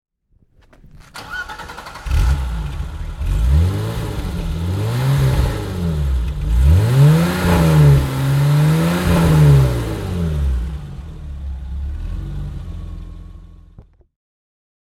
BMW 325i Cabriolet (E30, 1989) - Starten und Leerlauf